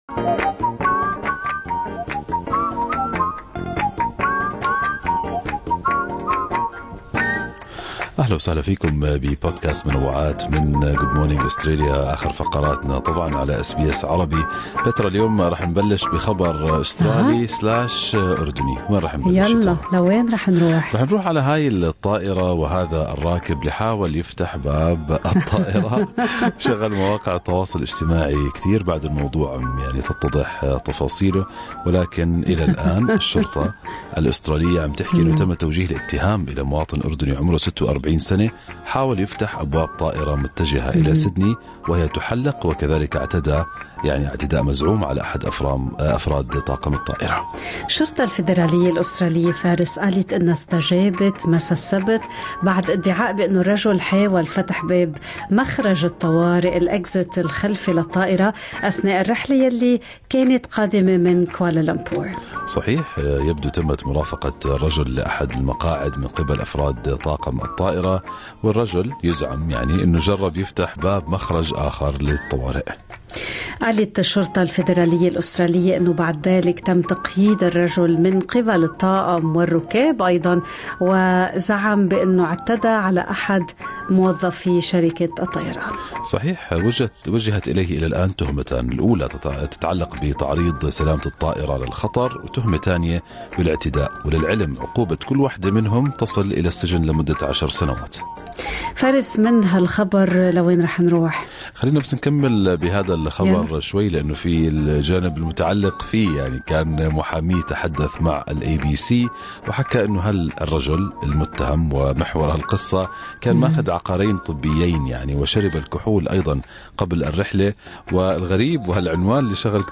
نقدم لكم فقرة المنوعات من برنامج Good Morning Australia التي تحمل إليكم بعض الأخبار والمواضيع الأكثر رواجاً على مواقع التواصل الاجتماعي.